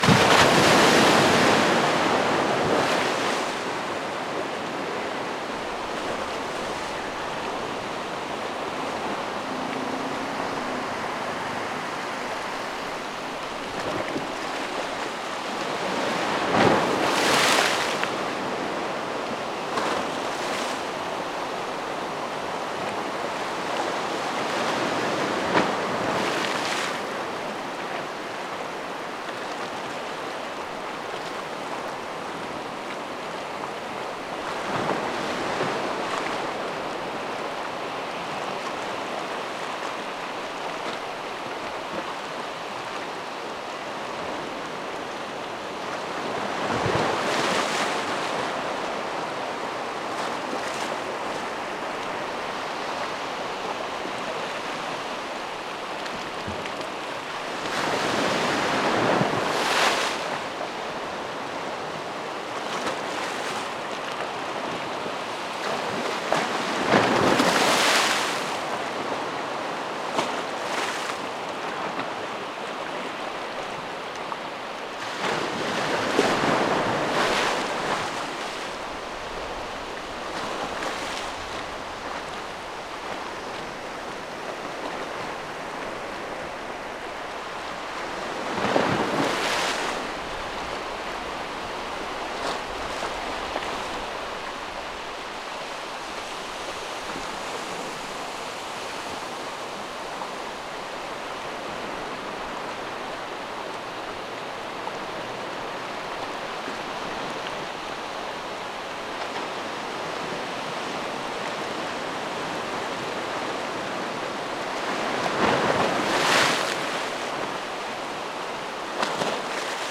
Chubut, Península Valdés, Puerto Pirámides
esa-chubut-peninsula-de-valdes-puerto-piramides.mp3